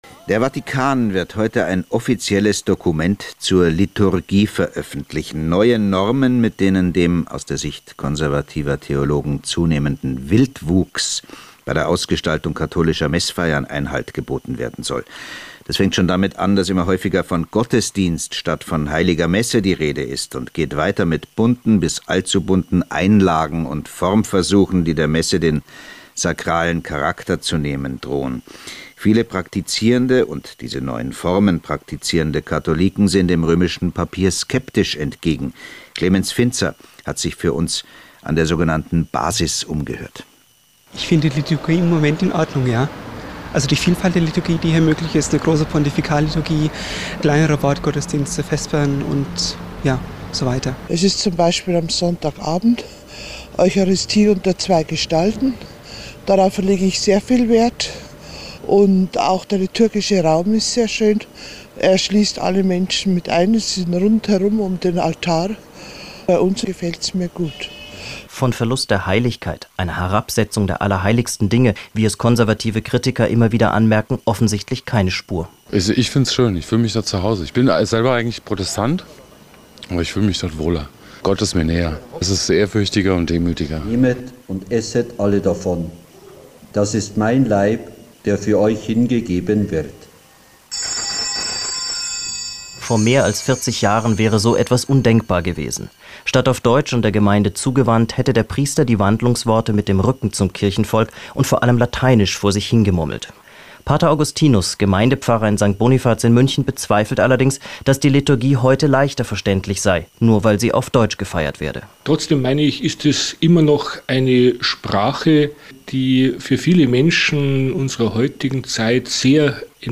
vor das Mikrofon geholt und mich zu meiner Meinung zum Applaudieren oder zu Gemütsäußerungen während des Gottesdienstes befragt.